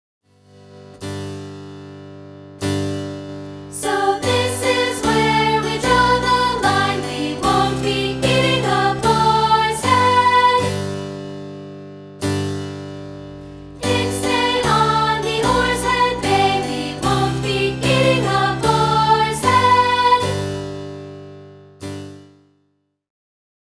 humorous carol
we've isolated the upper divisi part for rehearsal purposes.